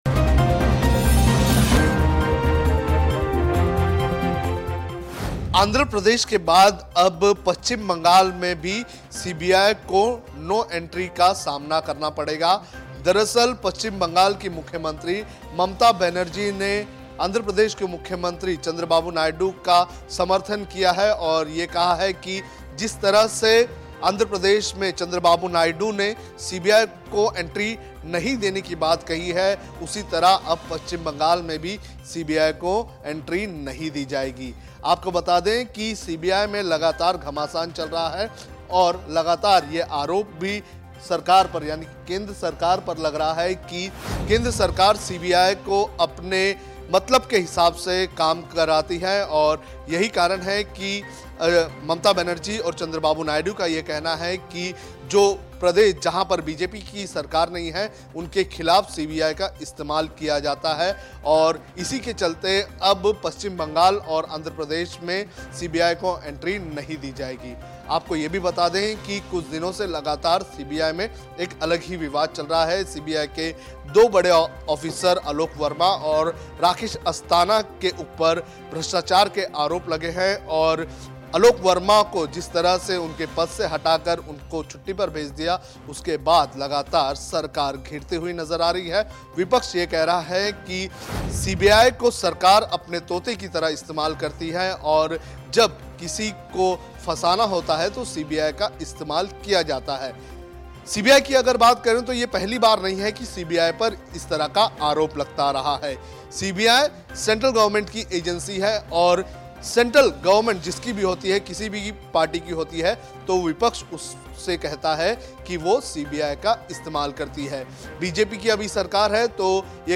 न्यूज़ रिपोर्ट - News Report Hindi / आंध्र प्रदेश के बाद बंगाल में भी सीबीआई को नो एंट्री